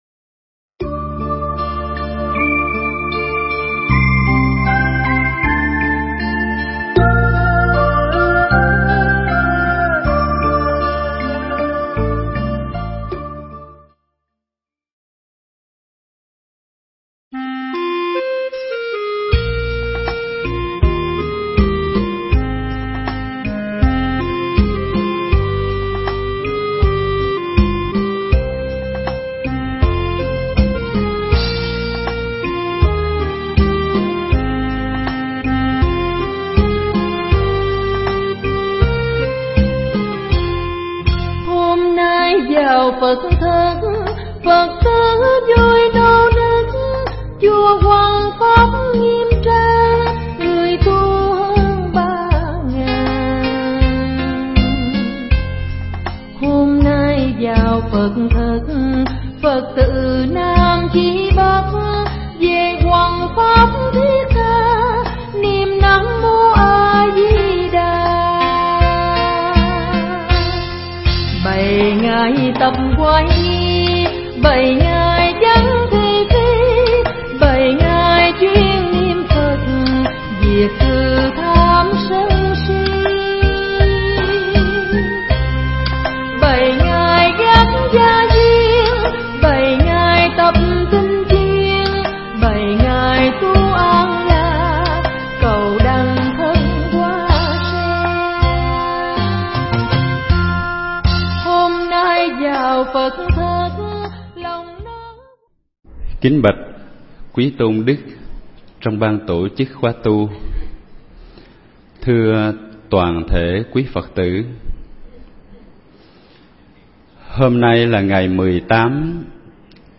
Nghe Mp3 thuyết pháp Nhận Diện Sự Sống